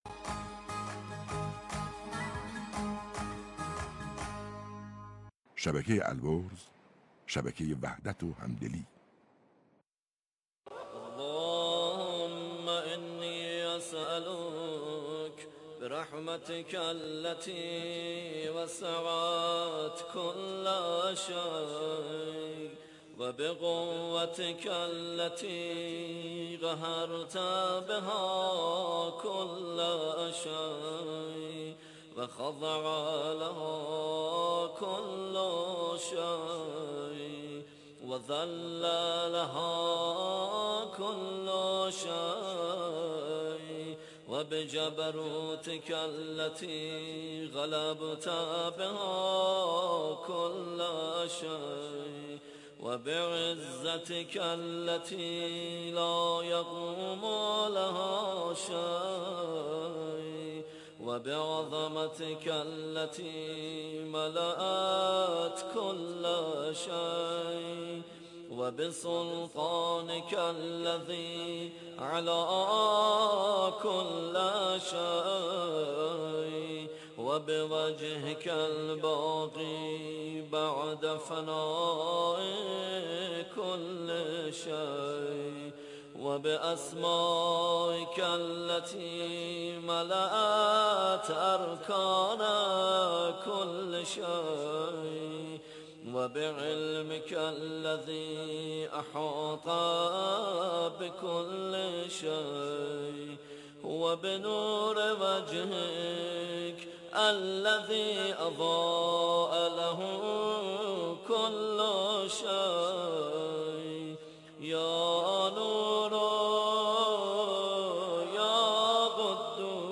دعای پرفیض کمیل را با نوای دلنشین